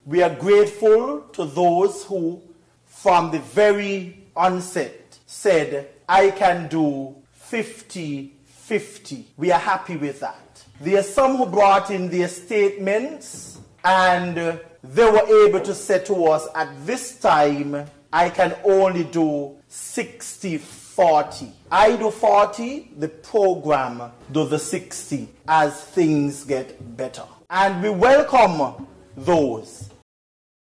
Deputy Prime Minister, Dr. Geoffrey Hanley.